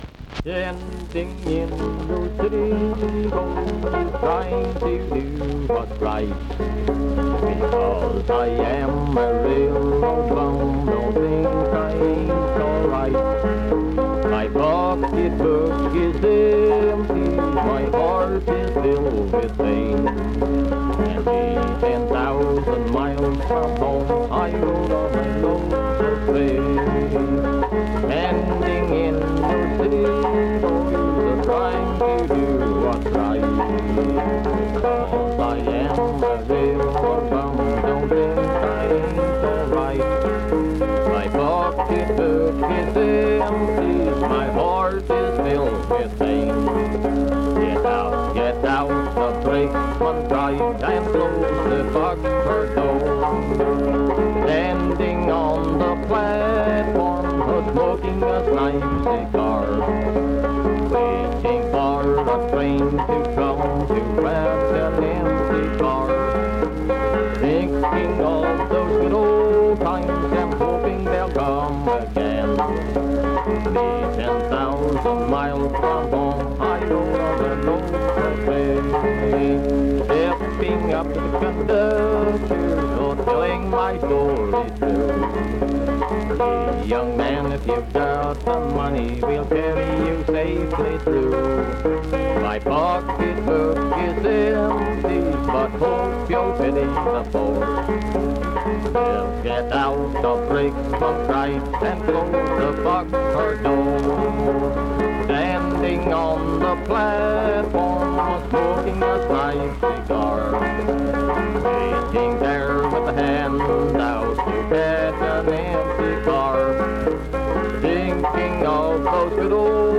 Vocal performance accompanied by banjo.
Voice (sung), Banjo